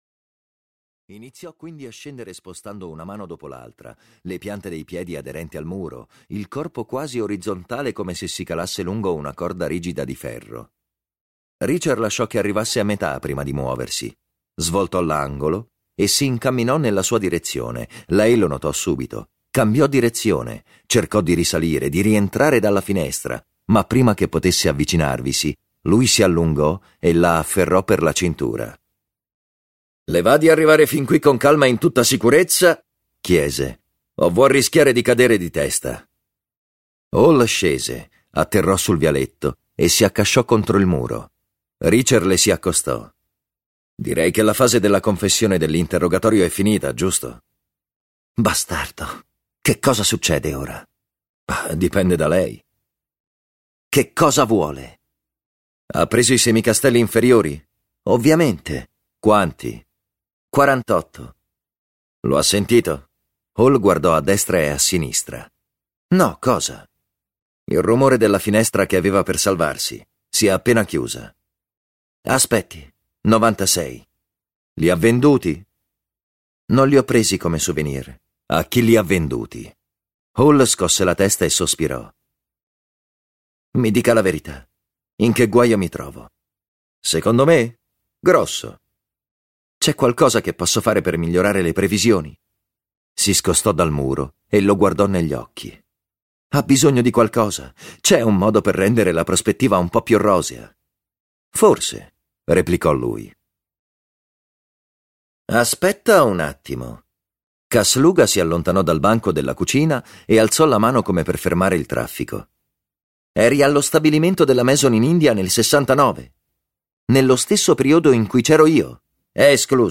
"Un segreto per Jack Reacher" di Lee Child - Audiolibro digitale - AUDIOLIBRI LIQUIDI - Il Libraio